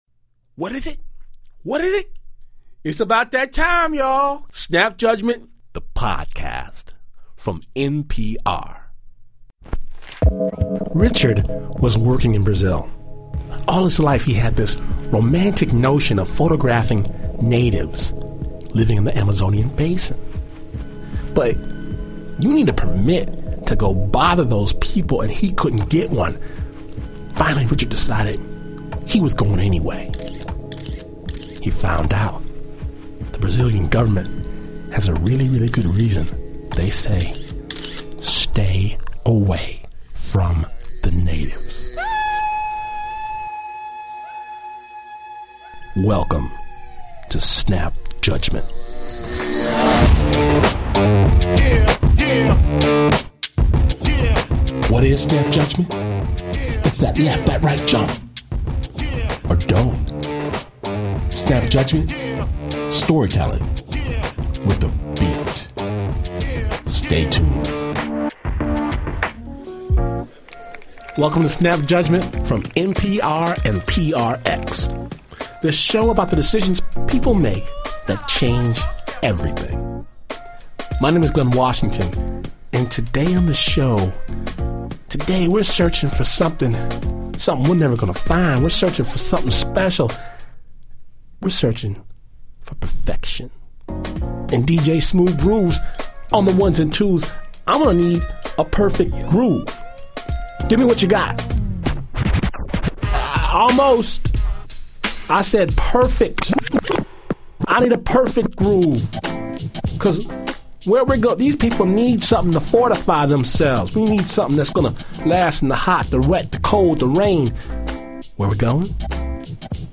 Snap Judgment (Storytelling, with a BEAT) mixes real stories with killer beats